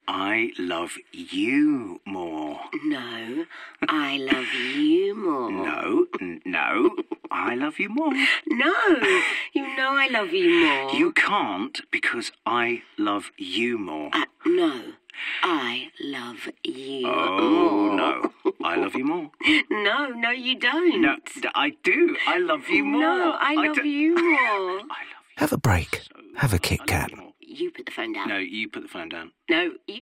KitKat-radio-Valentines-Day-CoverAs part of its successful Moment Marketing strategy for KitKat, J. Walter Thompson has created 2 tactical radio ads designed to point the brand’s witty observations directly at the most romantic of holidays.